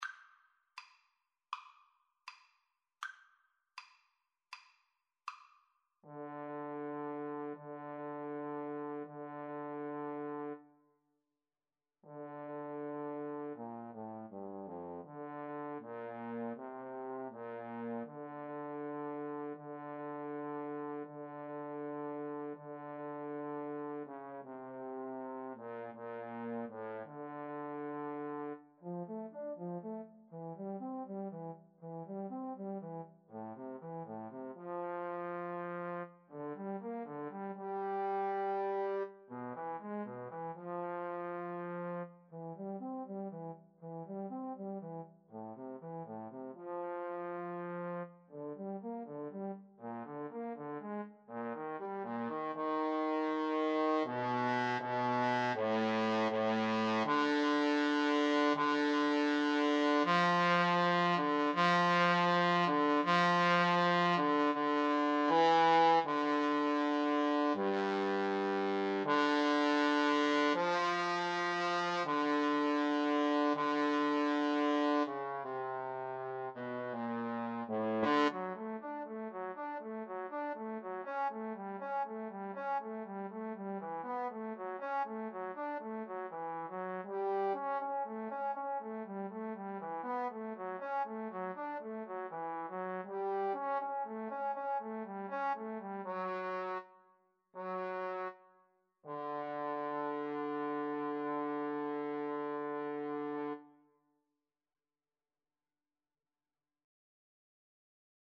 Andante = 80
Classical (View more Classical Trumpet-Trombone Duet Music)